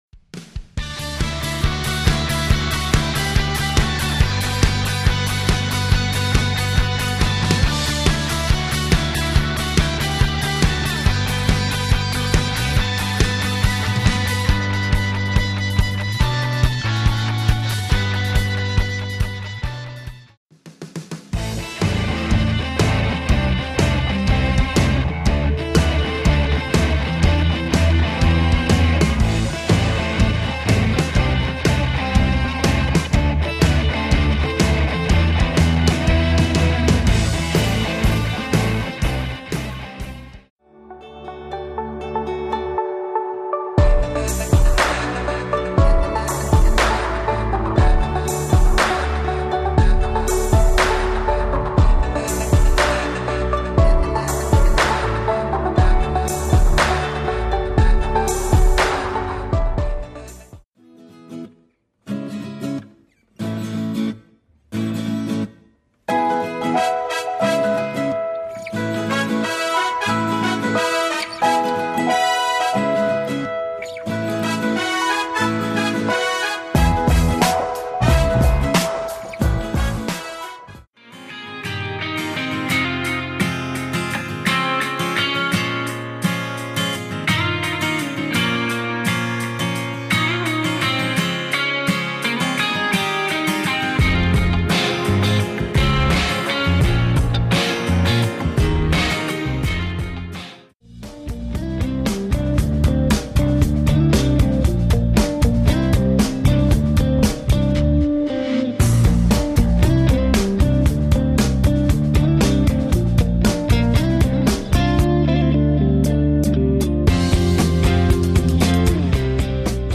styl - pop/dance/rock/clubbing